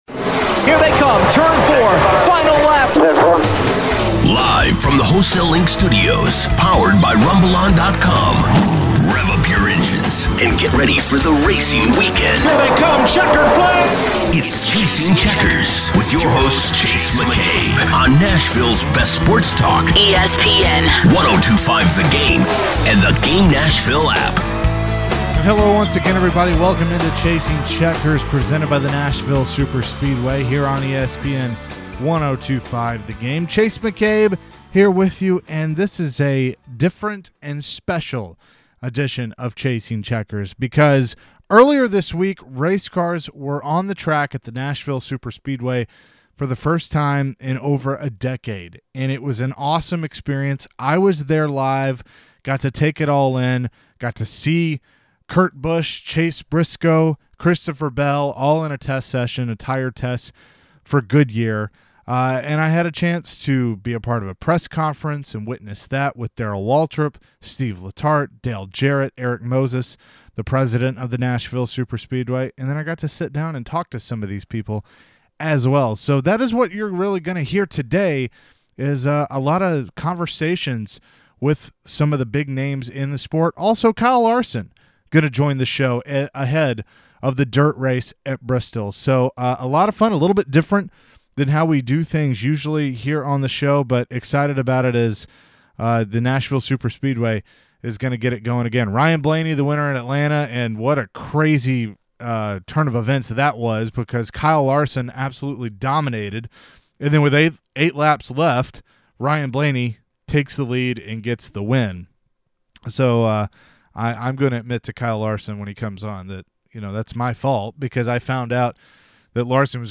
did the show from the Nashville Superspeedway as cars were on the track for the first time in over 10 years.